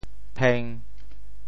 “姘”字用潮州话怎么说？
姘 部首拼音 部首 女 总笔划 9 部外笔划 6 普通话 pīn 潮州发音 潮州 pêng1 文 中文解释 姘〈动〉 (形声。